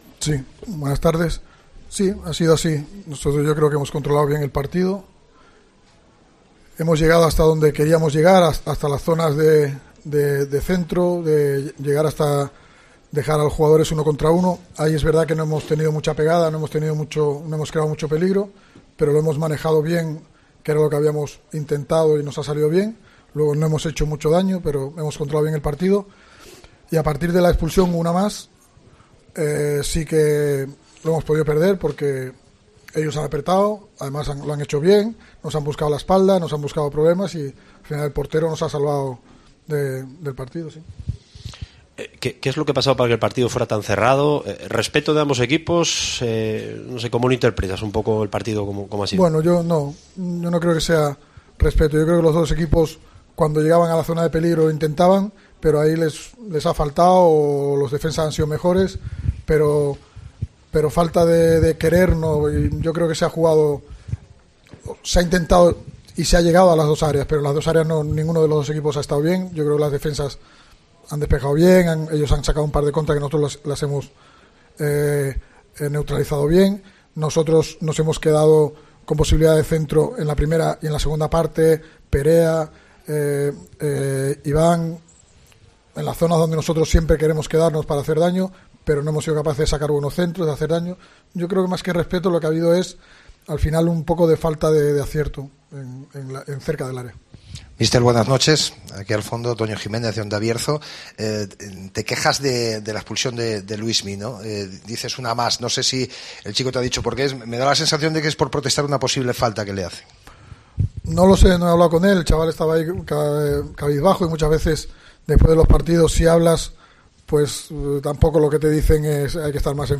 POSTPARTIDO
Escucha aquí al entrenador del Cádiz, Álvaro Cervera, tras el empate 0-0 en El Toralín ante la Ponferradina